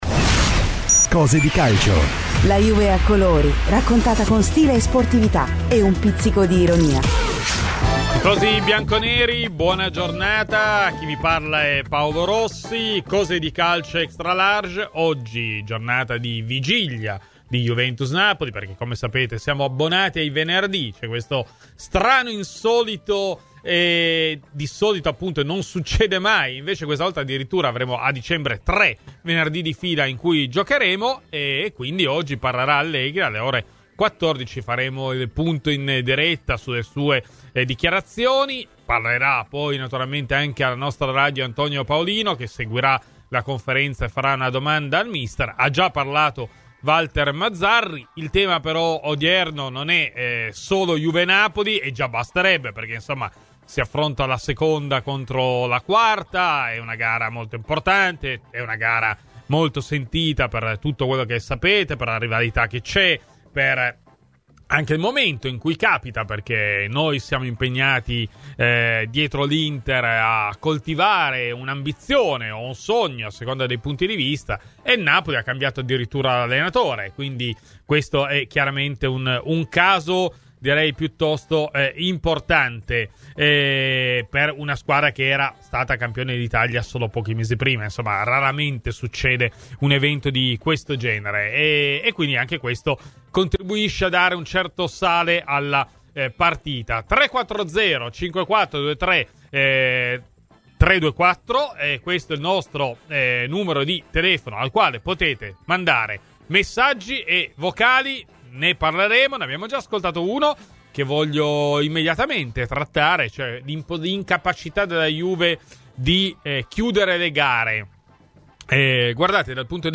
Ospite di Radio Bianconera nel corso di “Cose di Calcio”, il giornalista Xavier Jacobelli ha parlato del big match di domani